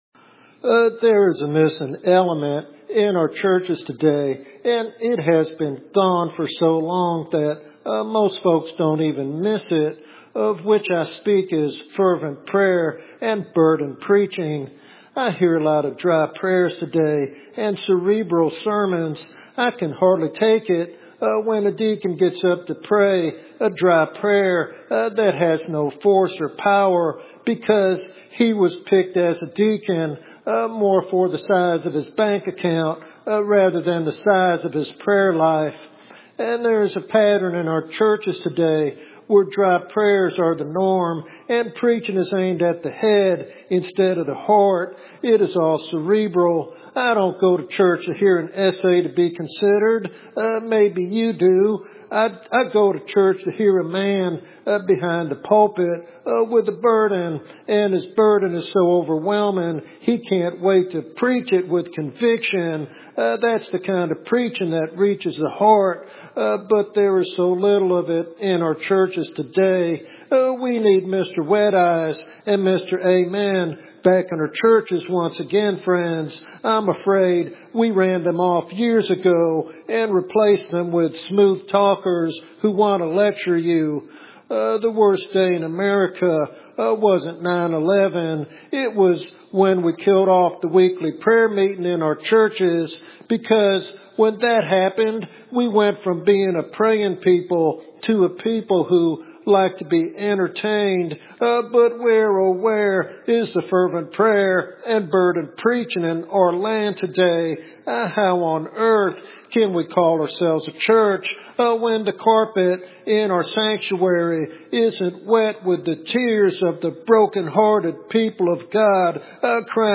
In this powerful sermon